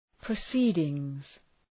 {prə’si:dıŋz}